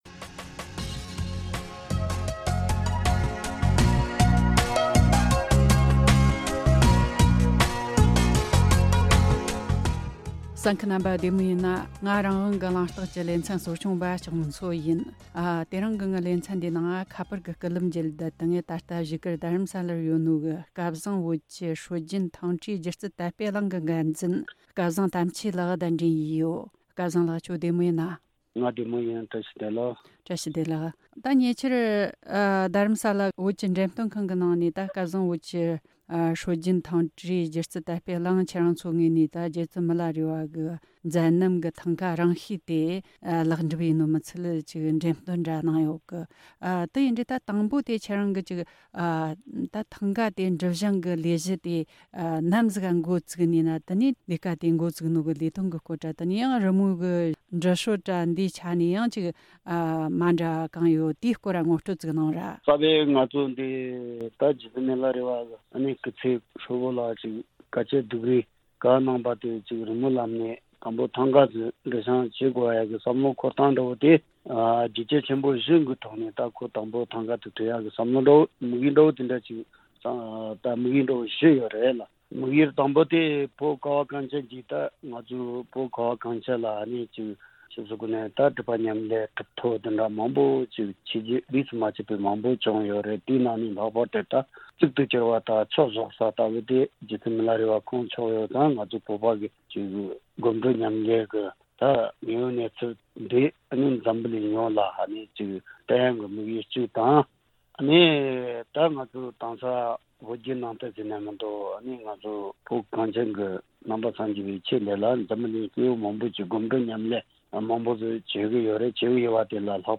གླེང་མོལ་བྱས་པ།